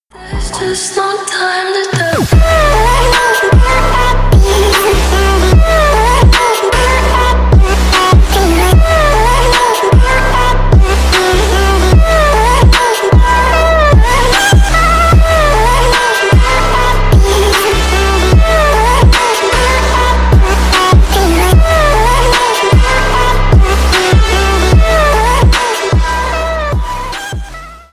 Ремикс
без слов